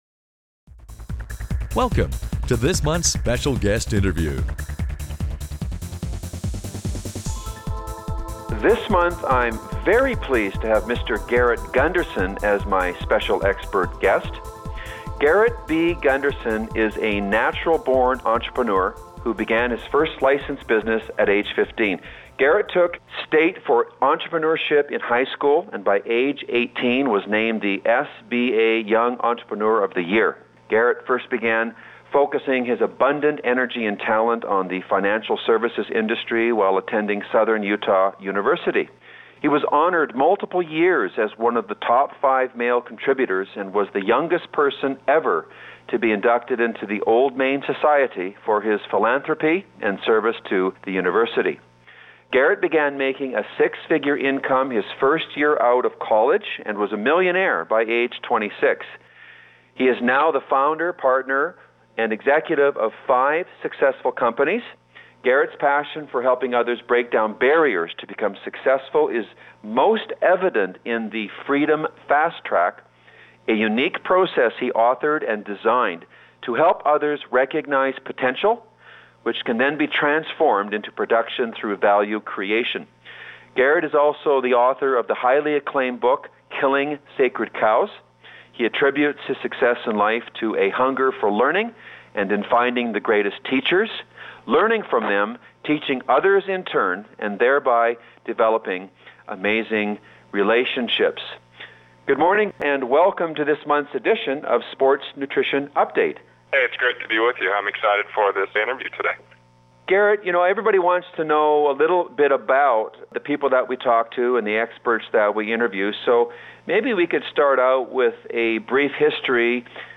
Special Guest Interview Volume 7 Number 12 V7N12c